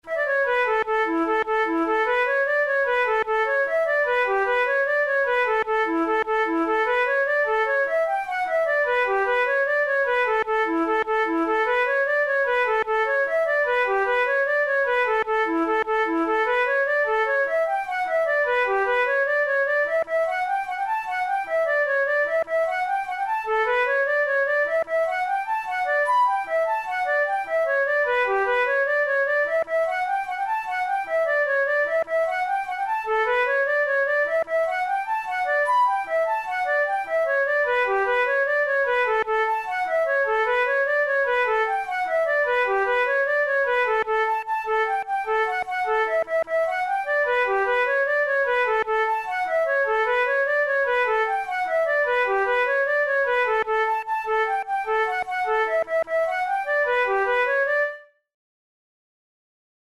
Traditional Irish jig
Categories: Jigs Traditional/Folk Difficulty: easy